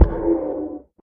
guardian_hit2.ogg